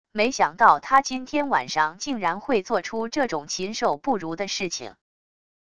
没想到他今天晚上竟然会做出这种禽兽不如的事情wav音频生成系统WAV Audio Player